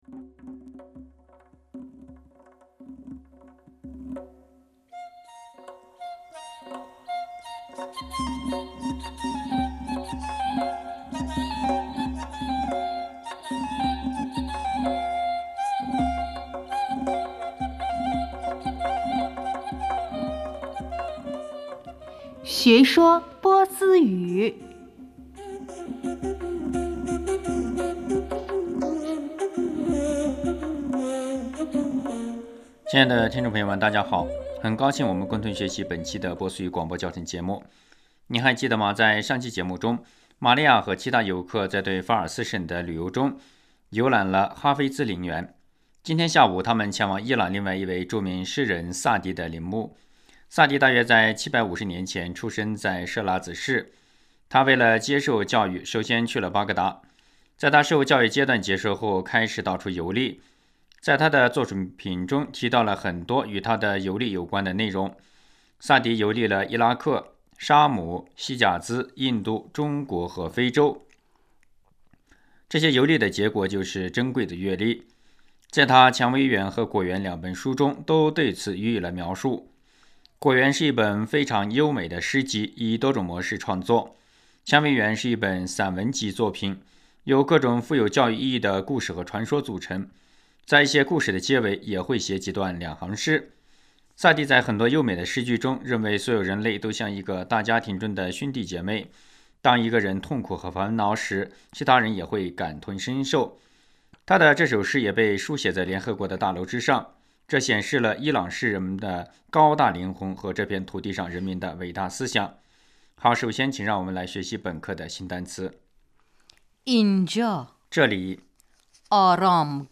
亲爱的听众朋友们，大家好！很高兴我们共同学习本期的波斯语广播教学节目。